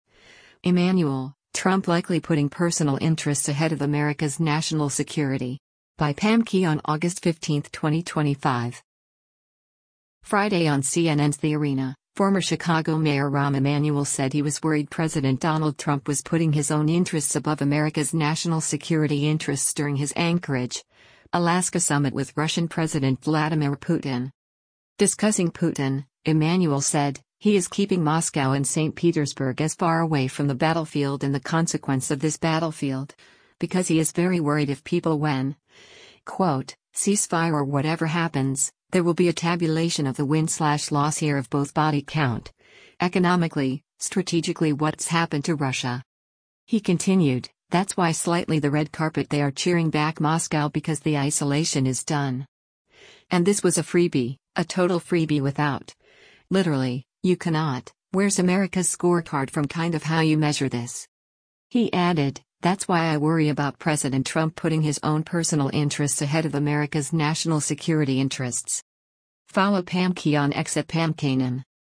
Friday on CNN’s “The Arena,” former Chicago Mayor Rahm Emanuel said he was worried President Donald Trump was putting his own interests above America’s national security interests during his Anchorage, Alaska summit with Russian President Vladimir Putin.